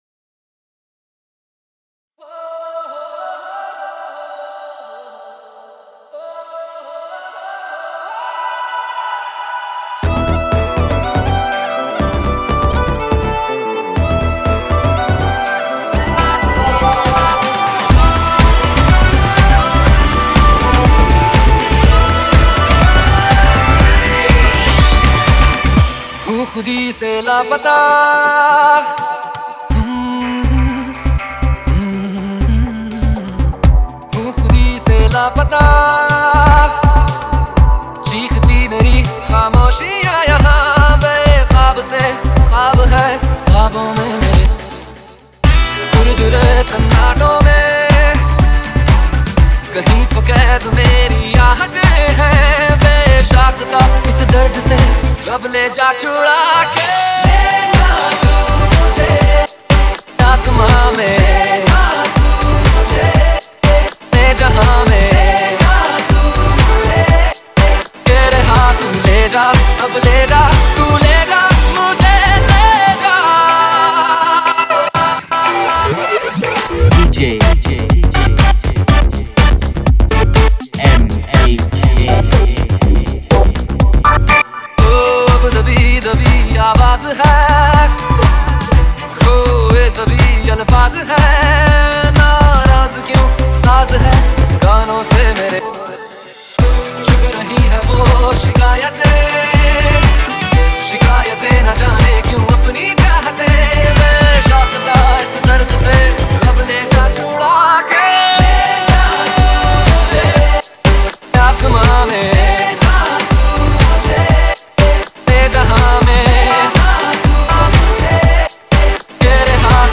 Latest Dj Mixes